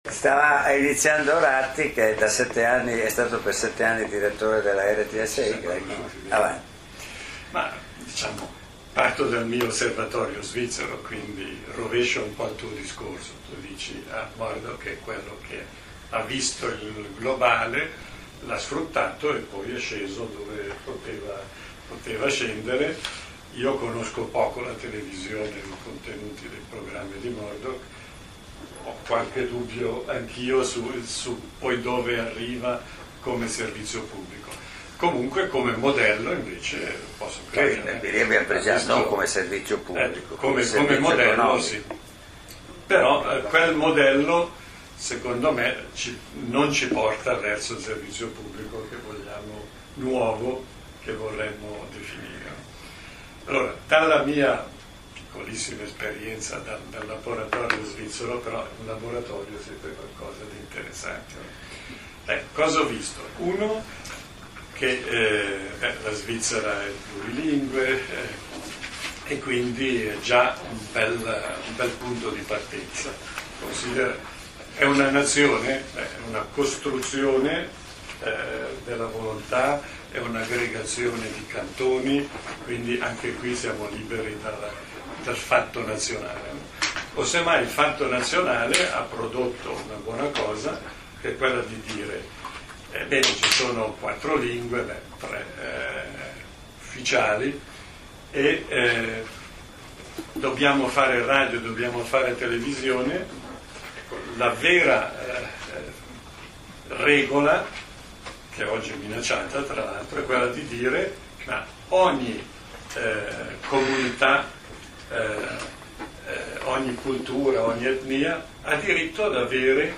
Seminario di Infocivica in collaborazione con Globus et Locus Milano - 15 gennaio 2009